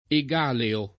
[ e g# leo ]